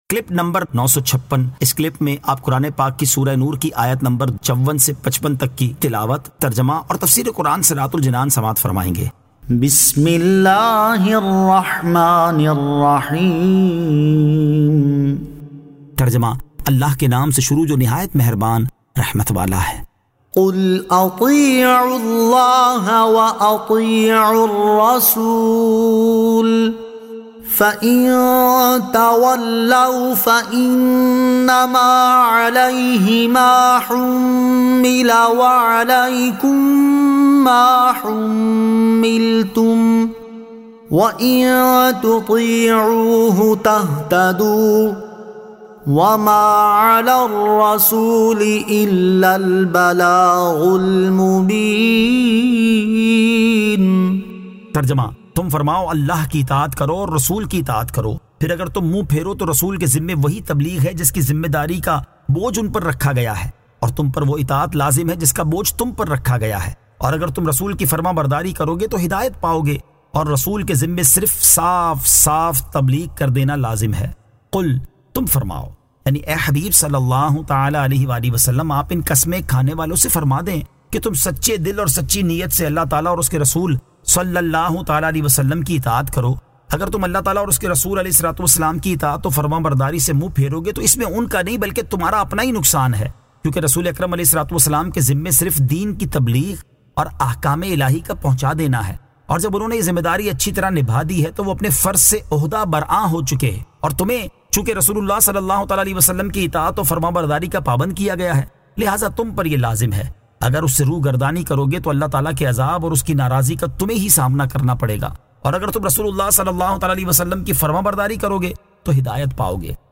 Surah An-Nur 54 To 55 Tilawat , Tarjama , Tafseer